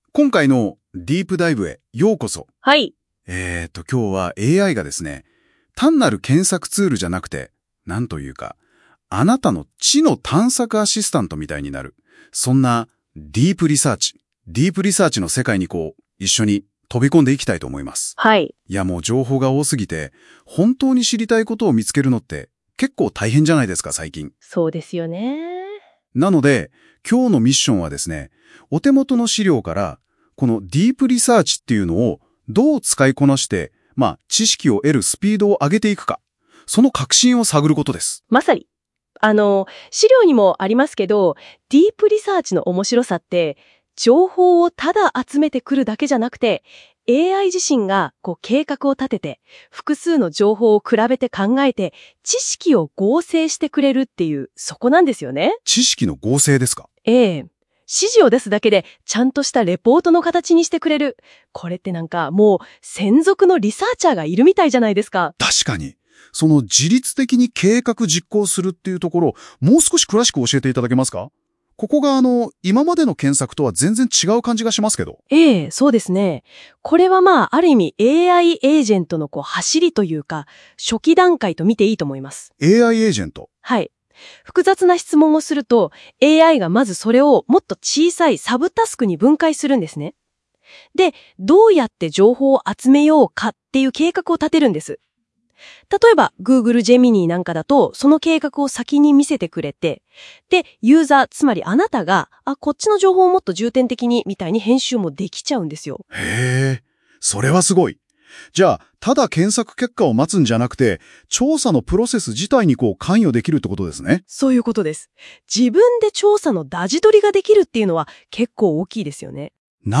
今回は音声解説を生成。
この音声解説でさえ数分で生成するのですから、Geminiはだいぶ進化してきましたね。